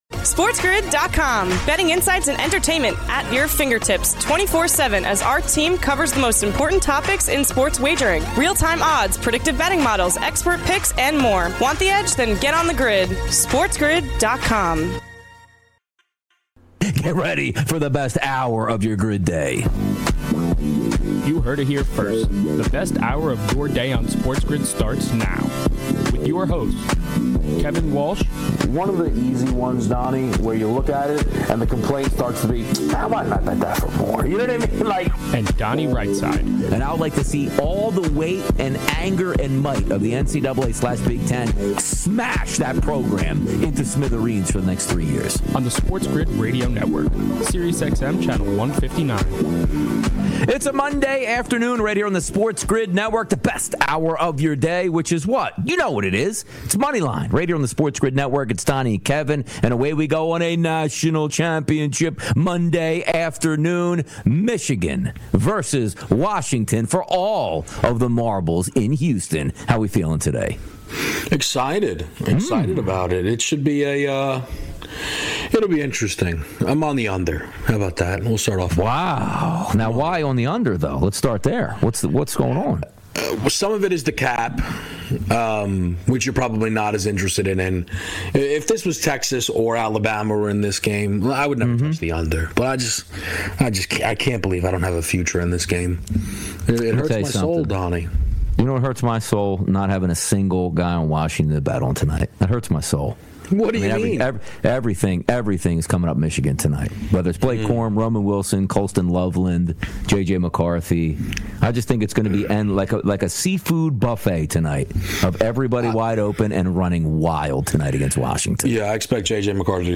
They also break down the total on the game and where they think the final score will land. They also make sure to take all your calls and predictions on the game.